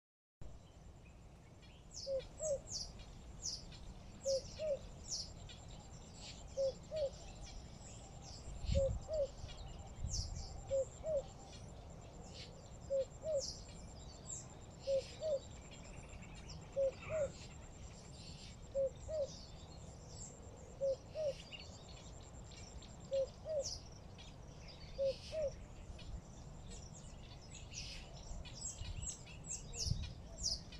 The call of the African Cuckoo is very like the Eurasian Cuckoo too, with a bit more emphasis on the second ‘syllable’ to my ear.